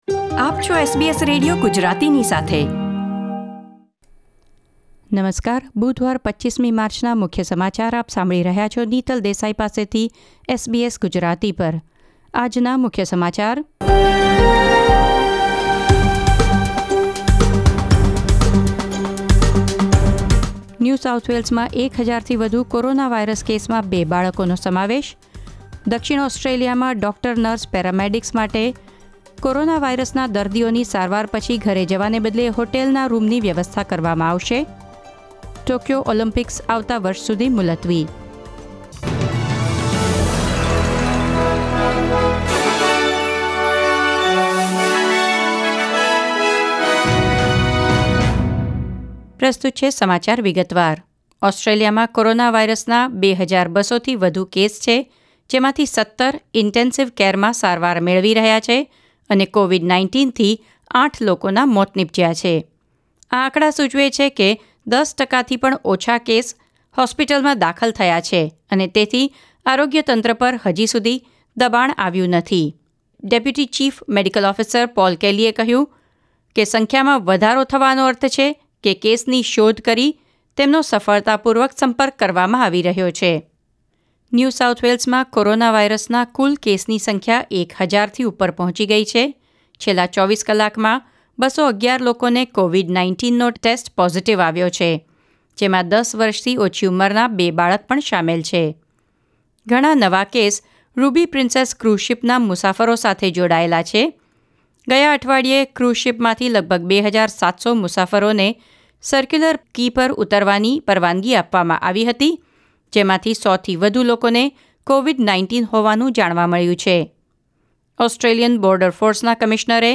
૨૫ માર્ચ ૨૦૨૦ના મુખ્ય સમાચાર